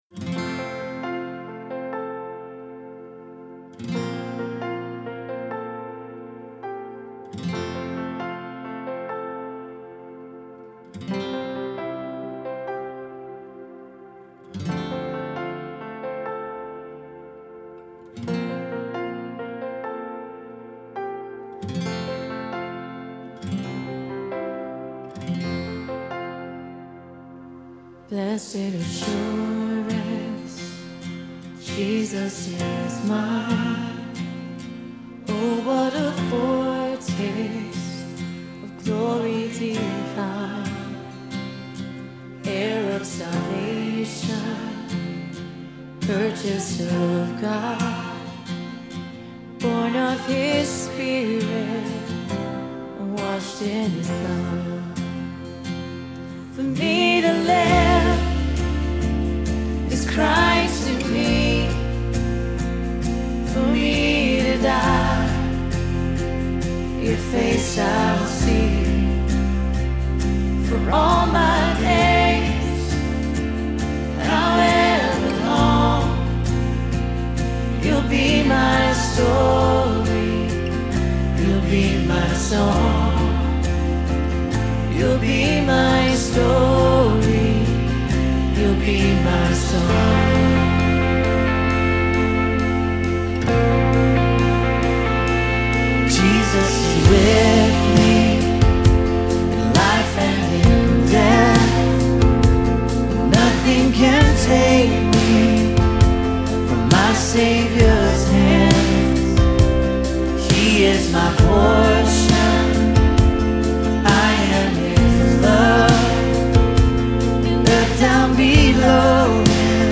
but knit together by a shared expression of worship.